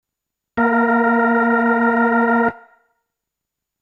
Für mich ist er unverändert zum Sound vor dem Beta-Update, das das Leslie-Problem mit dem 3.5er Board behebt.
Weiter oben gibt's ja auch Beispiele mit nur Percussion, und ich empfinde den Klang als eher random verzerrt, so in etwa als würden zwei digitale Audiogeräte ihren Sync nicht finden bzw. immer mal wieder verlieren.